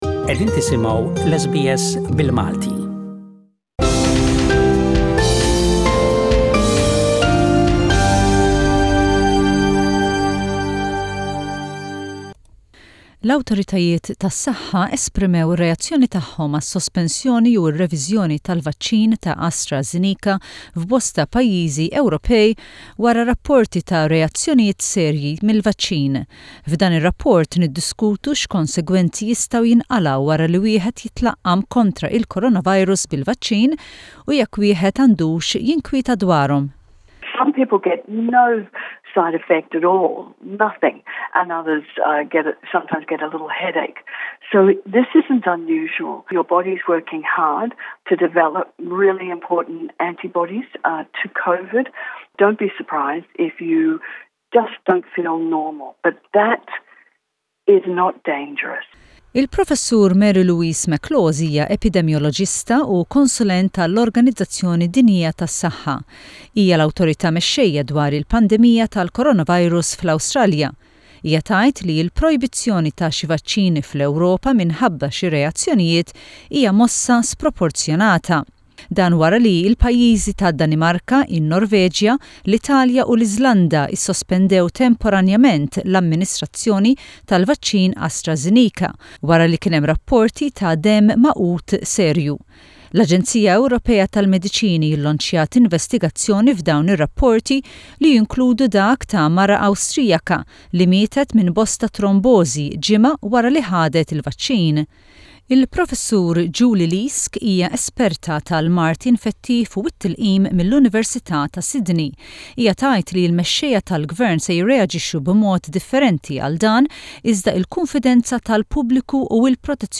A report on the negative effects that can arise from the coronavirus vaccine